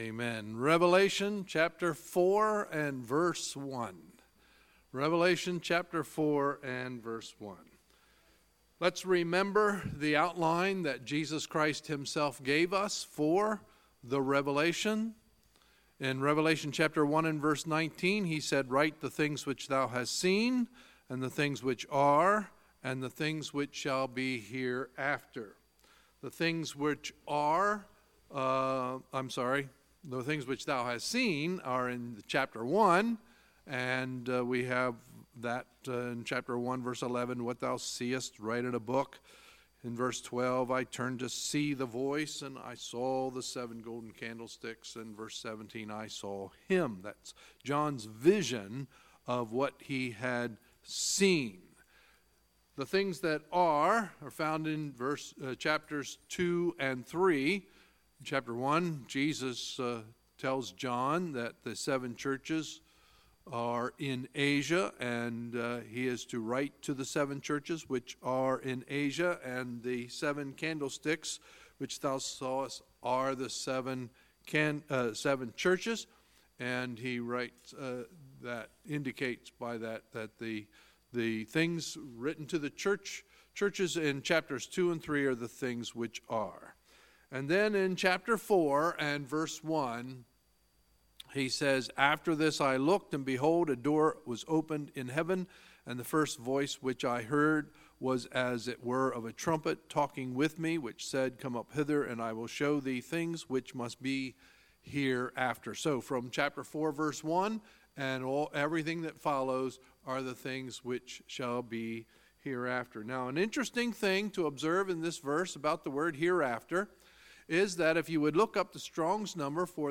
Sunday, April 29, 2018 – Sunday Evening Service